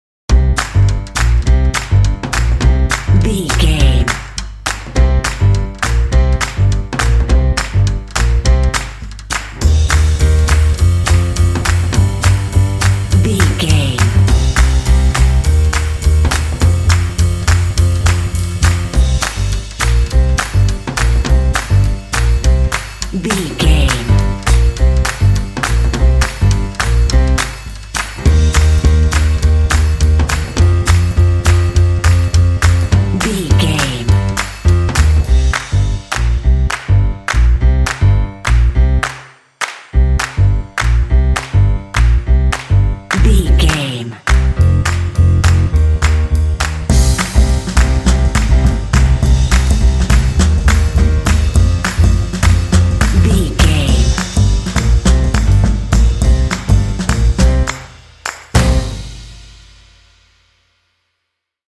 Aeolian/Minor
confident
determined
lively
drums
bass guitar
jazz